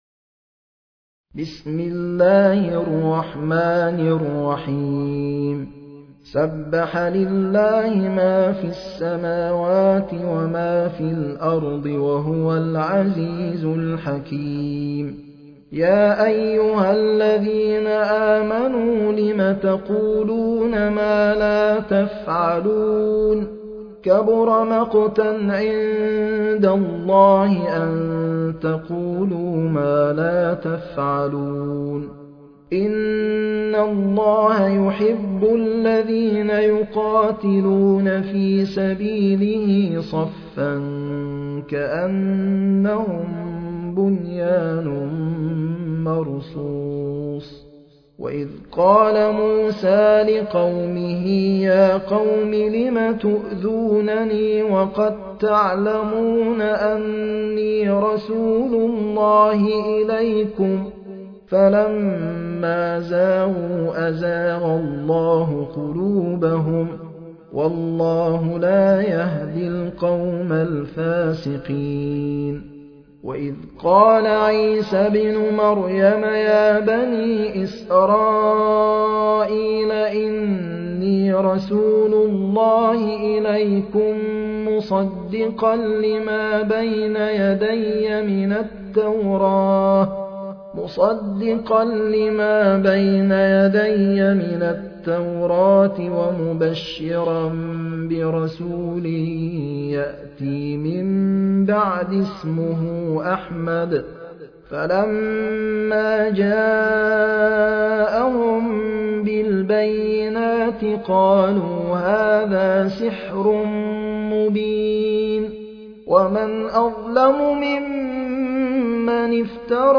المصحف المرتل - حفص عن عاصم - الصف